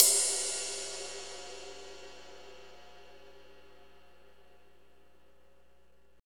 Index of /90_sSampleCDs/Northstar - Drumscapes Roland/DRM_Slow Shuffle/CYM_S_S Cymbalsx